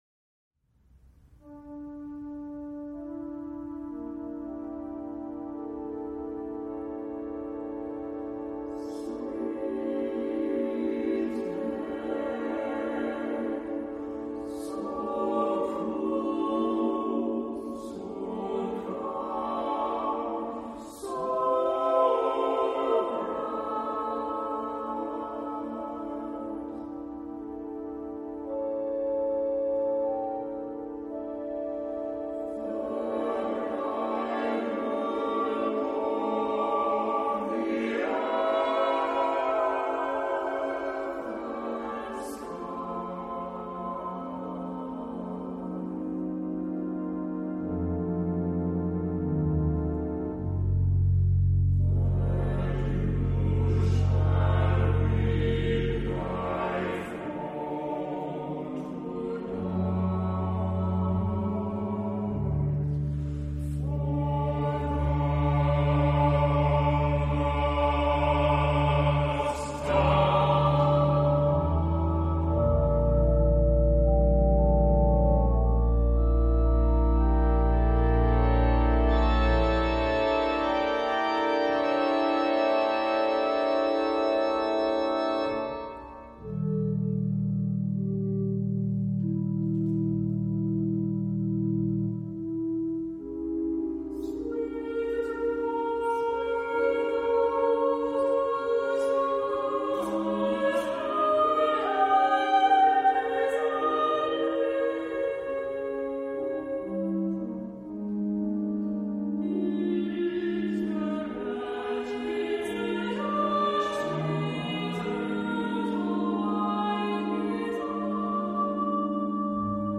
Genre-Style-Form: Secular ; Avant garde ; Poem
Mood of the piece: meditative
Type of Choir: SSAATTBB  (8 mixed voices )
Soloist(s): Soprano (2) / Alto (1)  (3 soloist(s))
Instrumentation: Organ  (1 instrumental part(s))
Tonality: free tonality